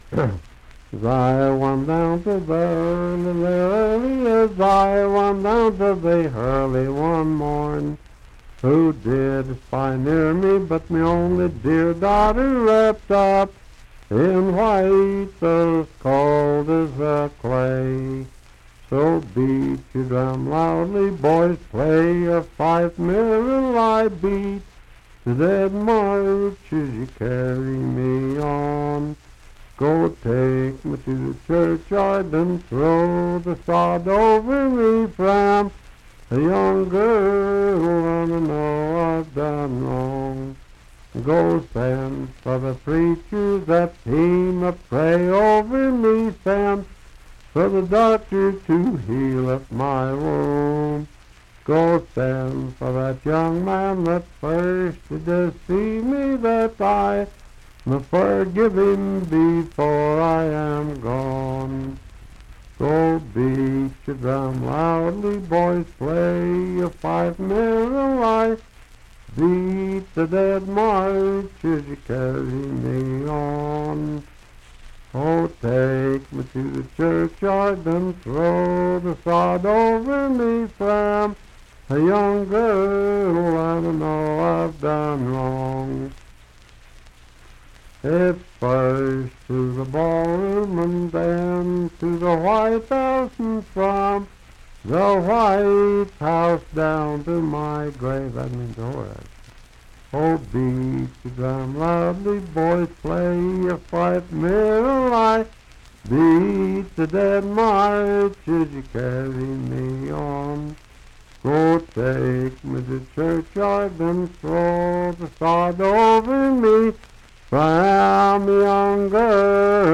Unaccompanied vocal music
Verse-refrain 4(4) & R(4).
Voice (sung)
Marion County (W. Va.), Fairview (Marion County, W. Va.)